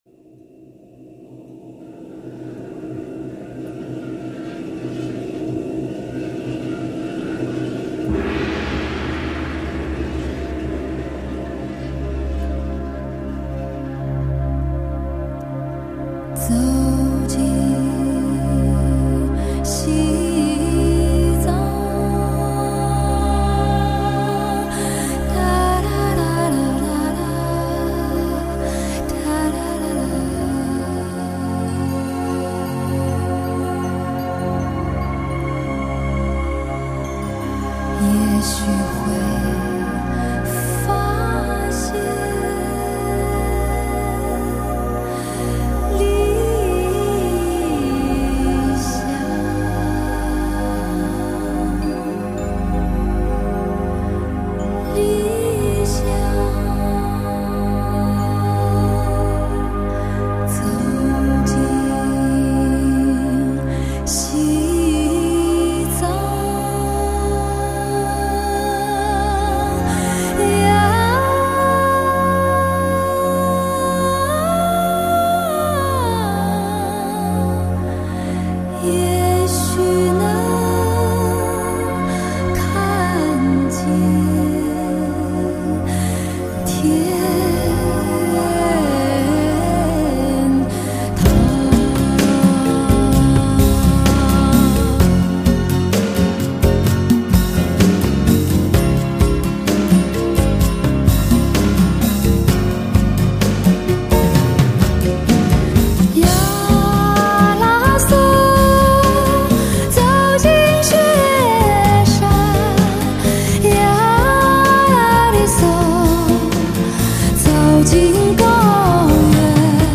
经典民谣
乡村民谣节奏元素